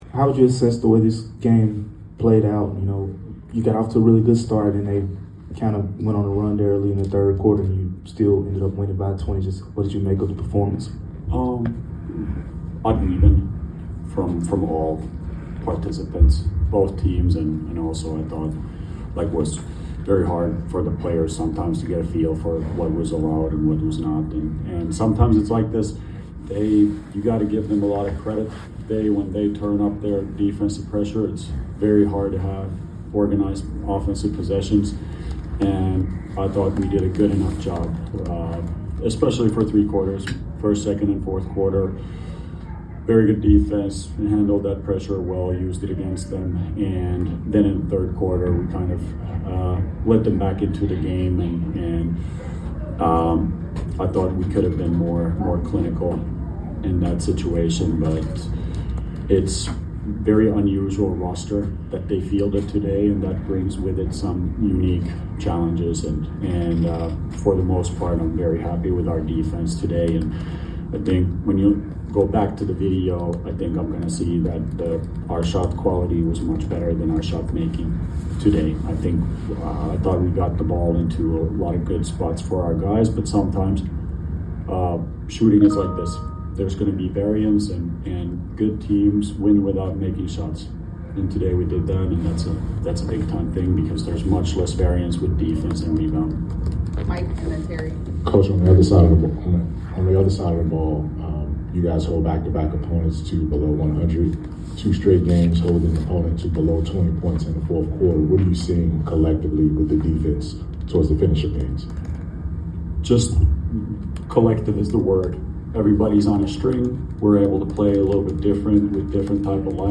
Memphis Grizzlies Coach Tuomas Iisalo Postgame Interview after defeating the Portland Trail Blazers at FedExForum.